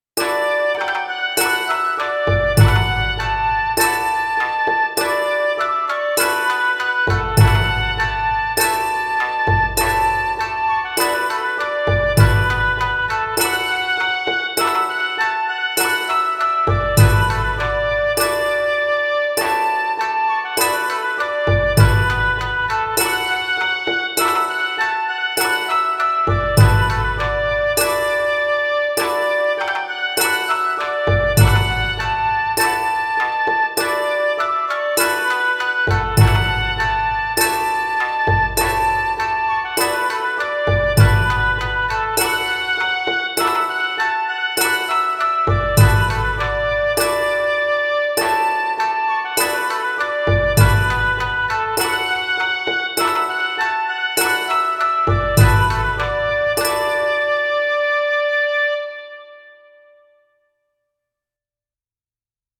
壱越調です（今で言うDメジャーみたいなもんです）
で、古代の雅楽譜を元にして、見よう見まねで作ったのが以下の音源です。
聴いていただければ分かるとおり、とってもポップで明るい！！です！
楽器は、篳篥の音をオーボエに、笛をピッコロに、琵琶を三味線にしてます。